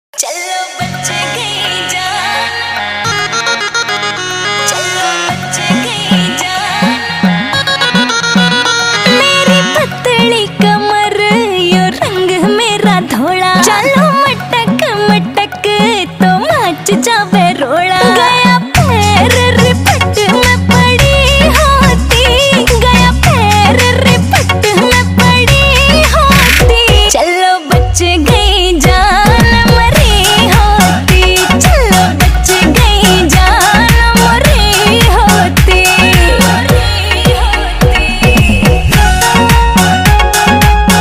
Haryanvi hit ringtone
energetic Haryanvi track